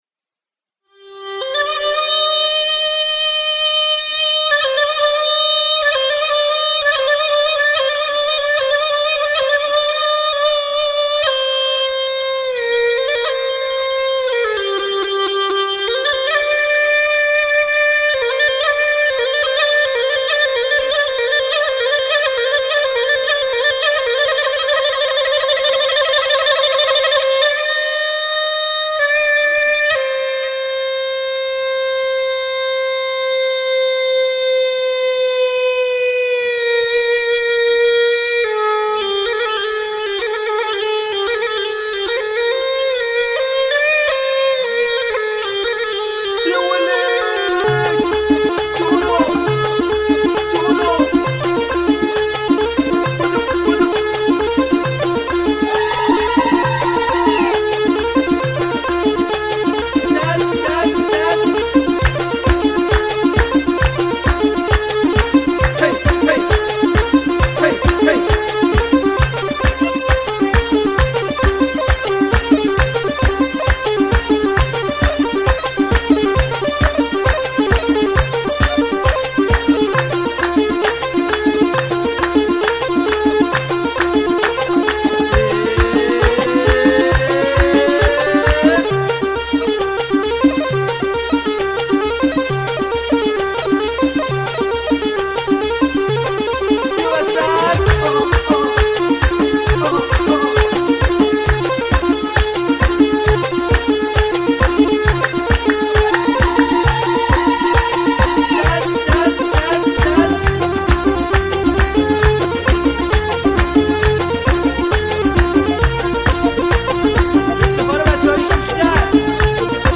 آهنگ ریمیکس